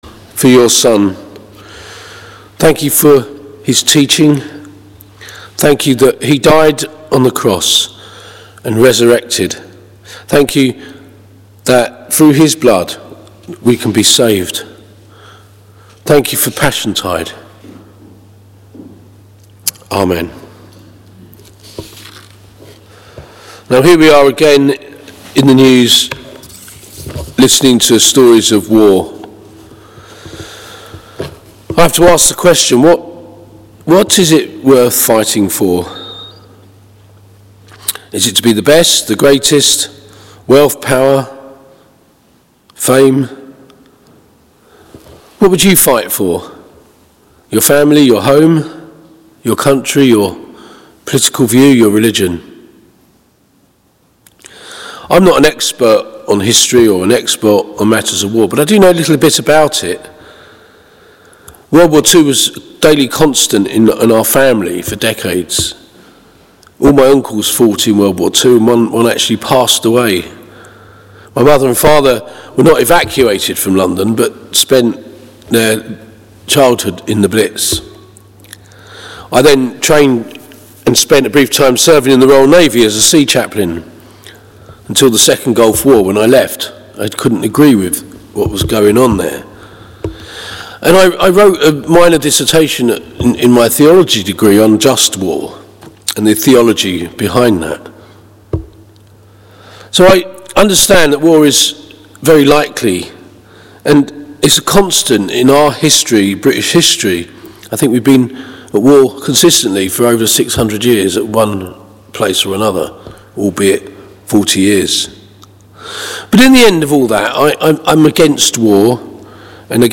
Please listen to our 8am Sermon here:
Passage: Galatians 6:11-18 Service Type: Morning Worship